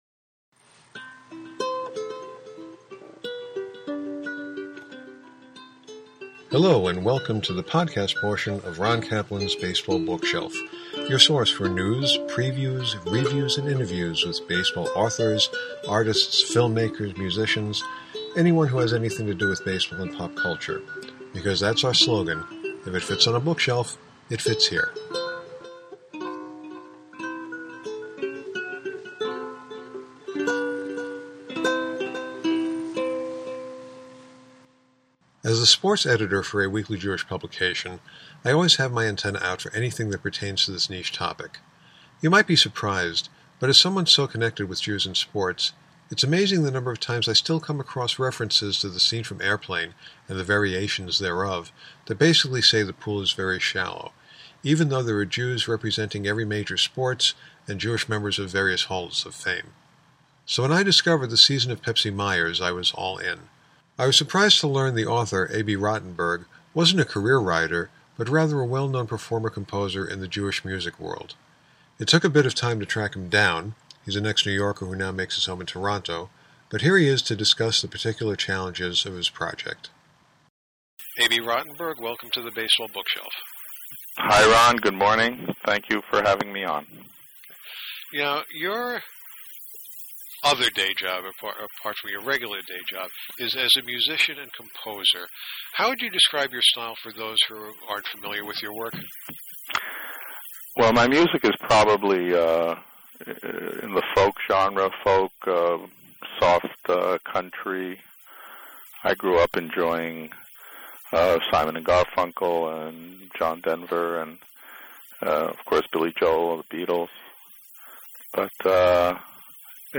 The Bookshelf Conversation